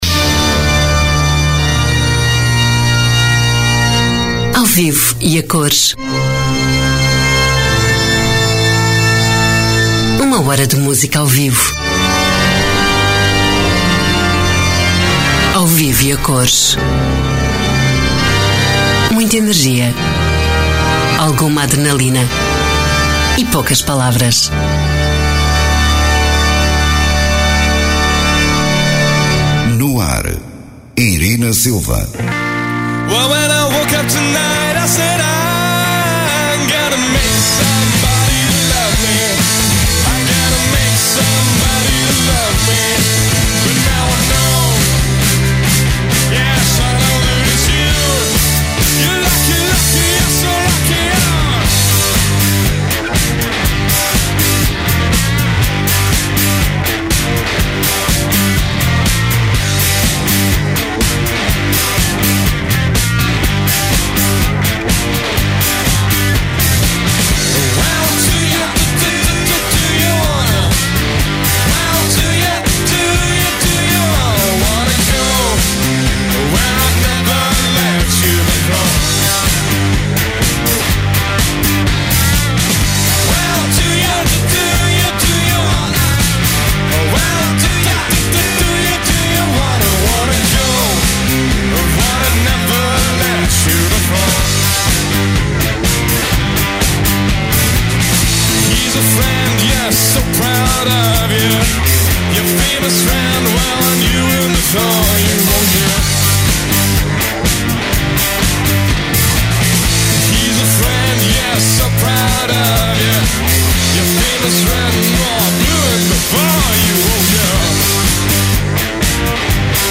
A mística dos Concertos